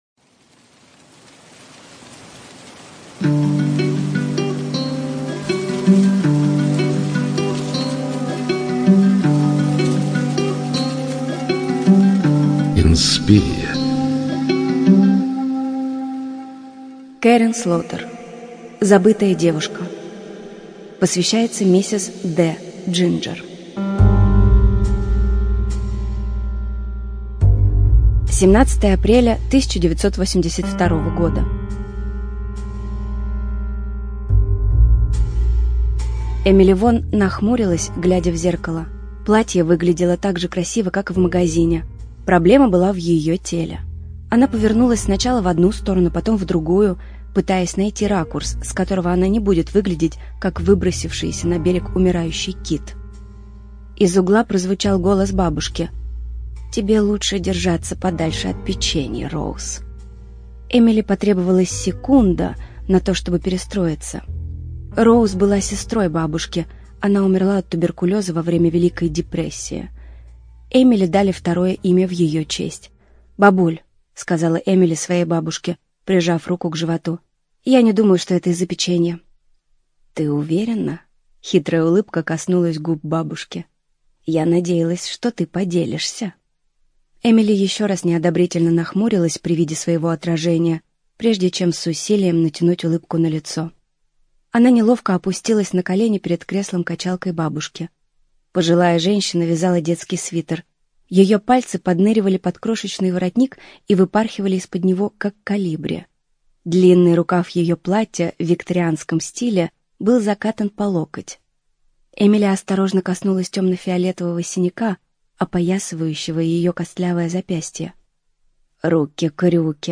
Студия звукозаписиInspiria